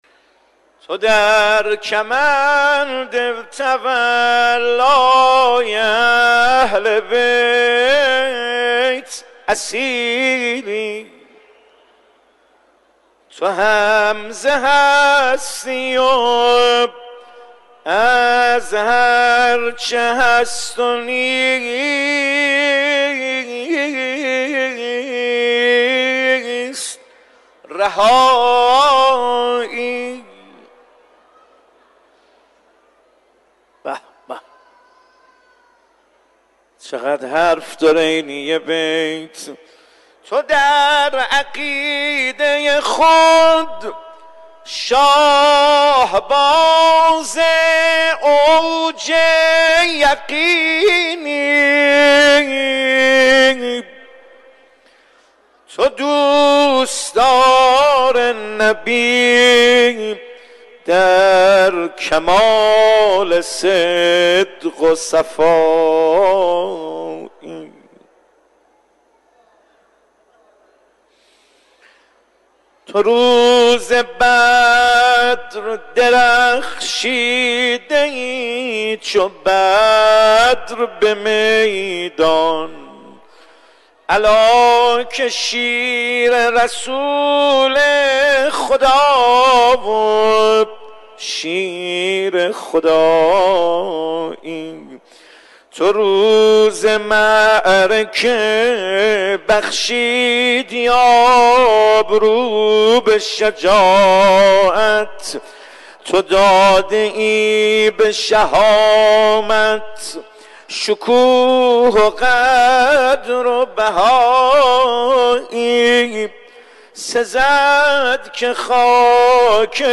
در حرم مطهر رضوی